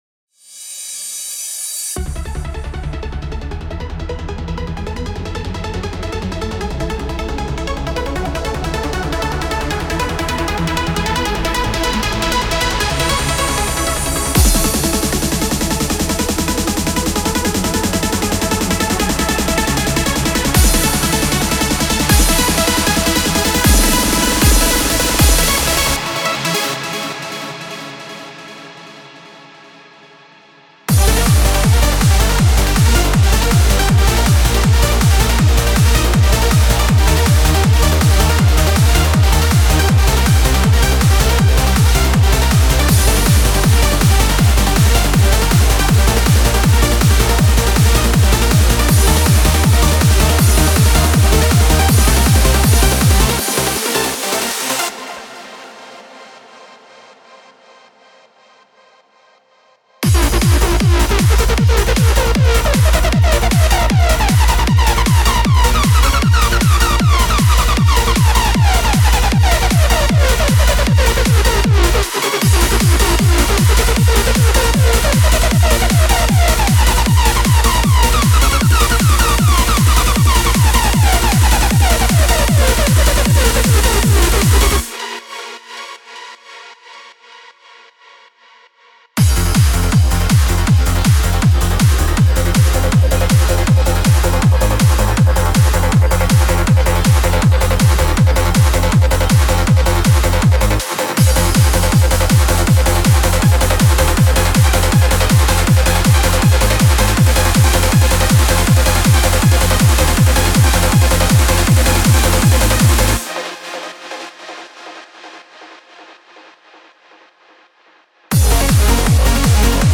Hardcore / Hardstyle Trance Uplifting Trance
Demo Mix Down of Each Kit.